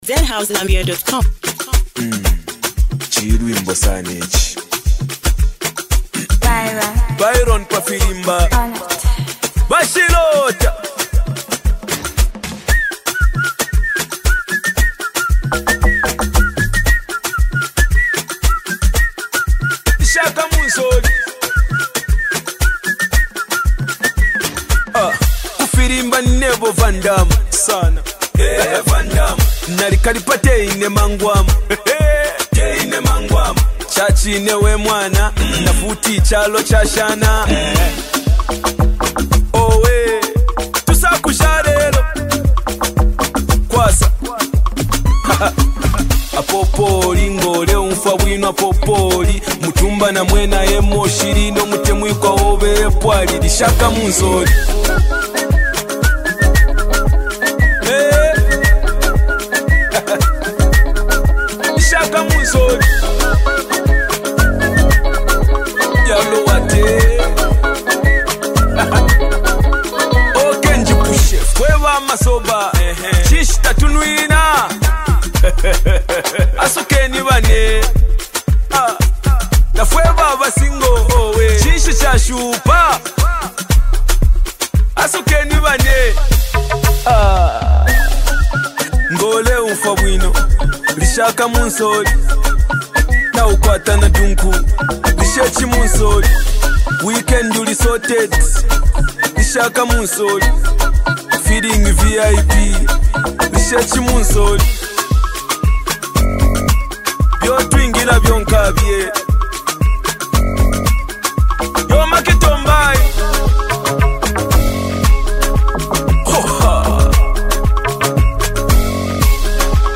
With smooth vocals and an infectious rhythm